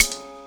6 Foyer Rimshot 2.wav